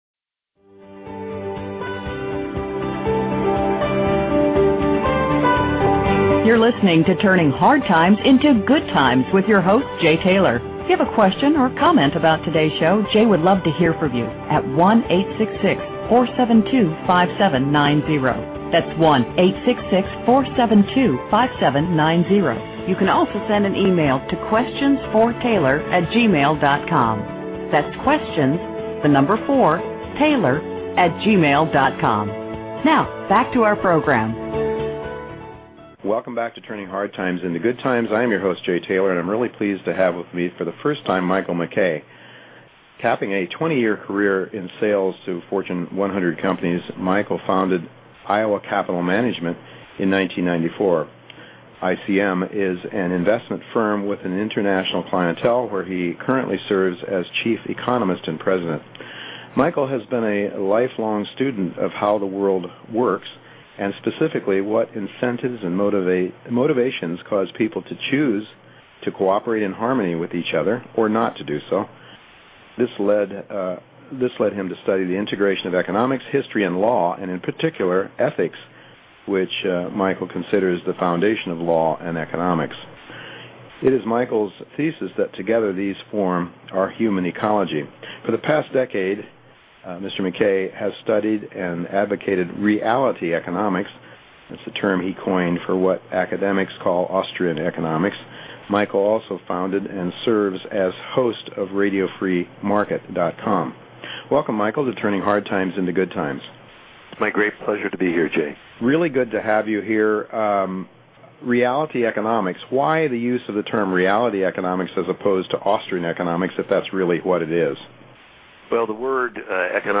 This interview provides fascinating insights into Money, Gold, Banking and how Reality Economics will prevail.